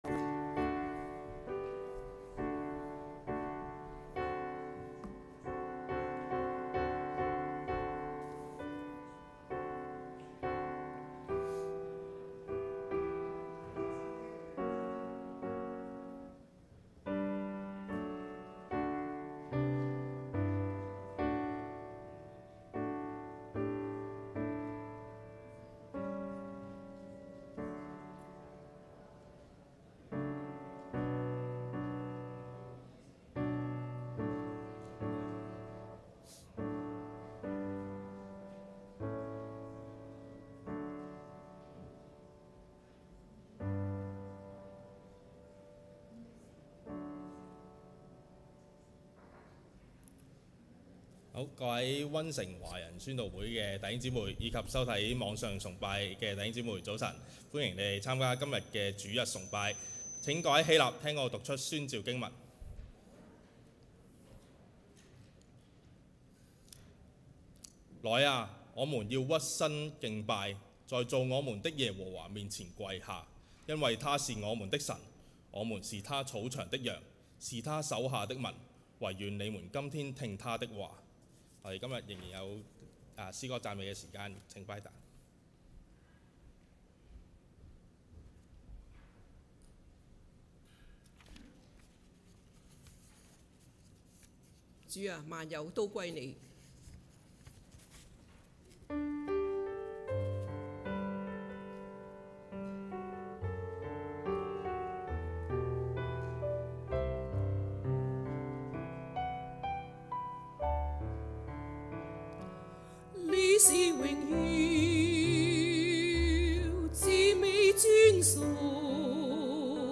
2026年3月1日溫城華人宣道會粵語堂主日崇拜